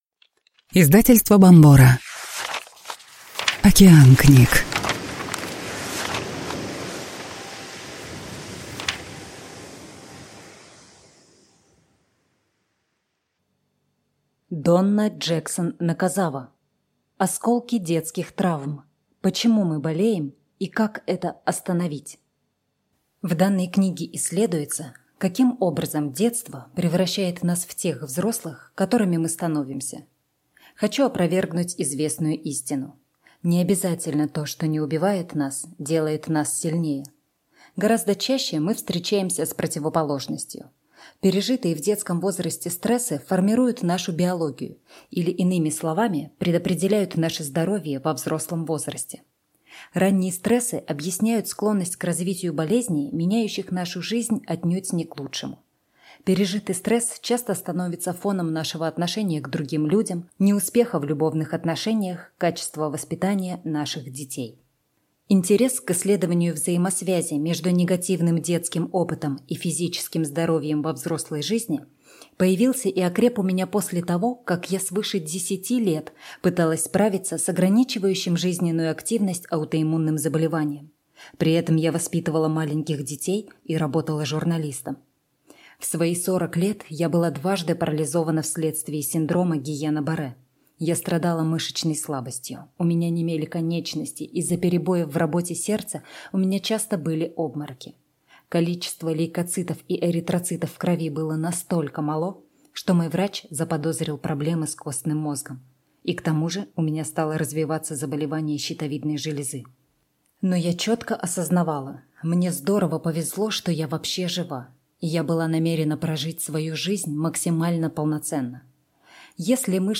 Аудиокнига Осколки детских травм. Почему мы болеем и как это остановить | Библиотека аудиокниг